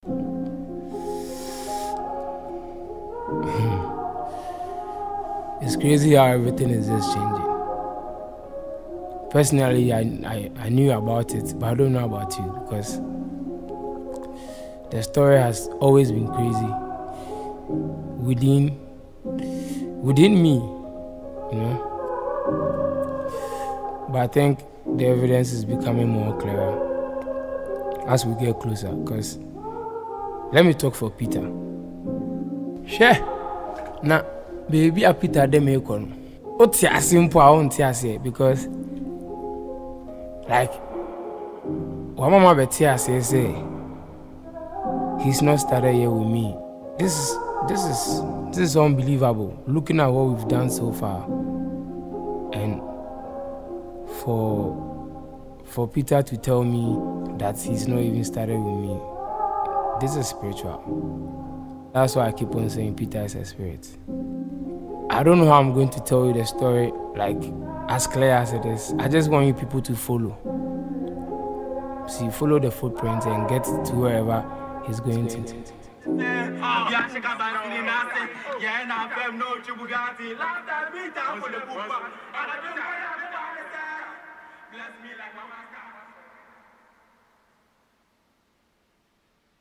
a renowned Afrobeat singer